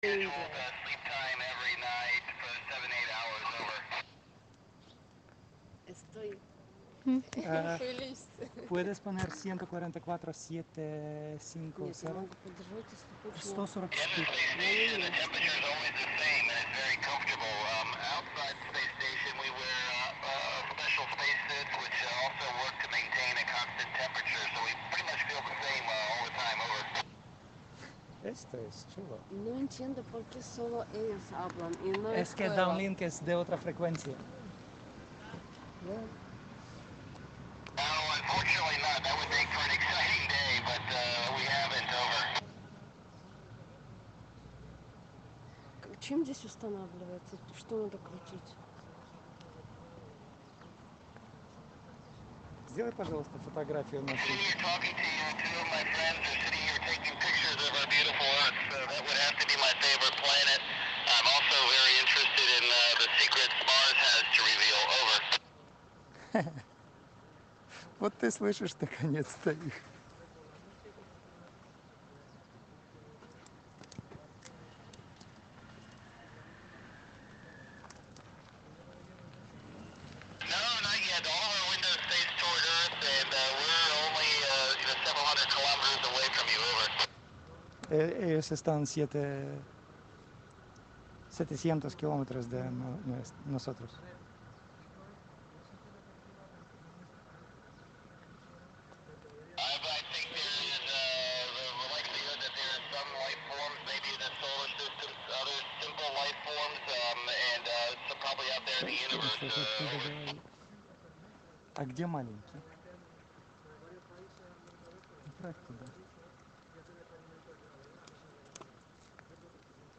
YAGI 3 elements measuring tape antenna
Baofeng radio...